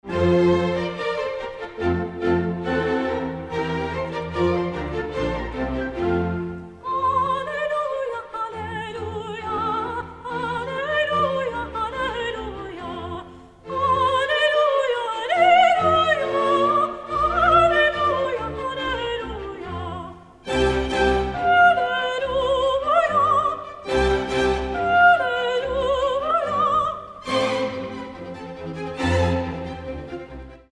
One of Mozart’s most famous works—with good reason. This church solo from Mozart’s teenage years rivals his secular arias for sheer brilliance.